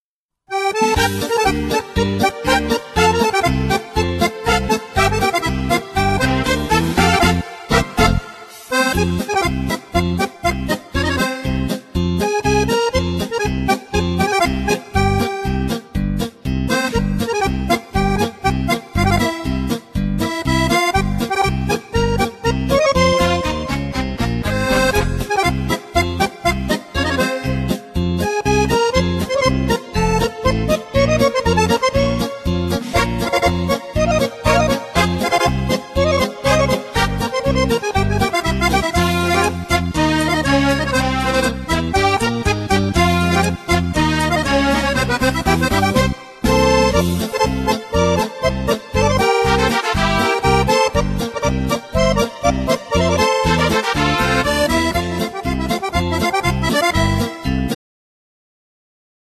Genere : Liscio - Folk